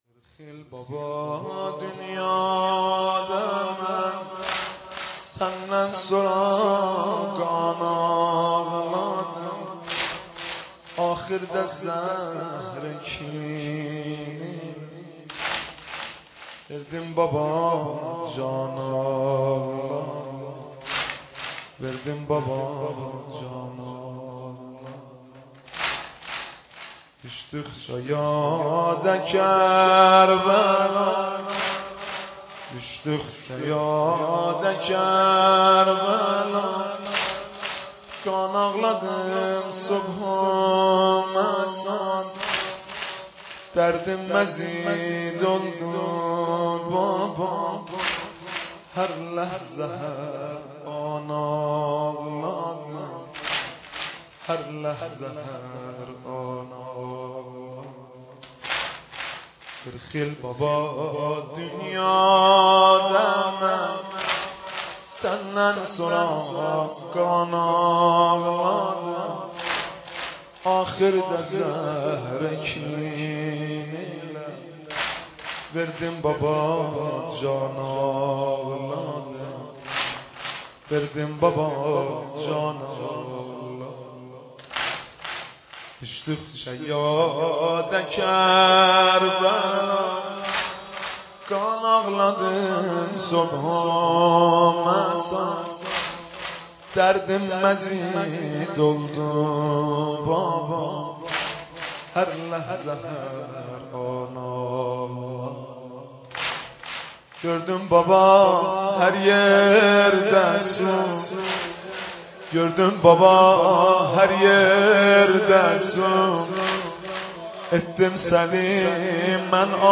سینه زنی واحد زیبا آذری